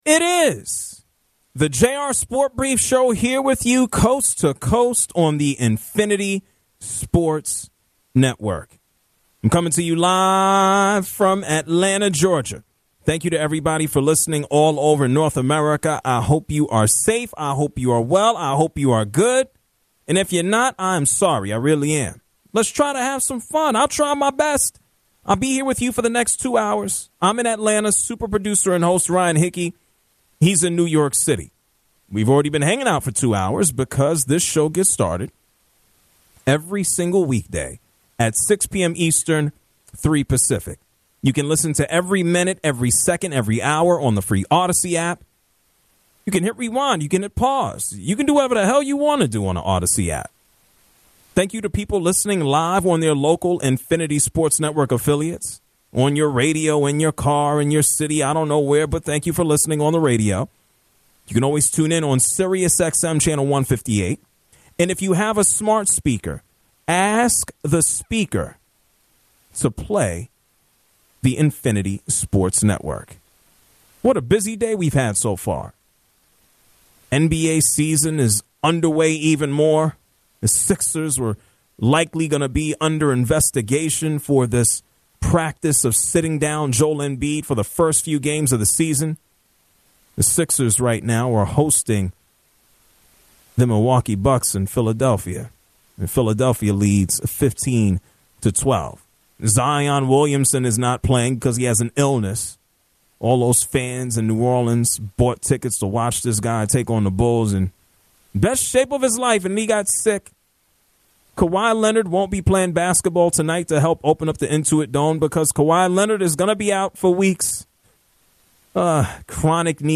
The hour concludes with callers weighing in on the greatest father/son combos in sports.